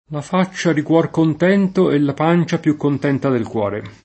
cuor contento [kU0r kont$nto] (meno com. cuorcontento [id.] e corcontento [kqrkont$nto]) s. m. — es.: un vero corcontento [un v%ro kqrkont$nto] (Giusti); un esercito di cuorcontenti [un e@$r©ito di kUqrkont$nti] (Nievo); la faccia di cuor contento e la pancia più contenta del cuore [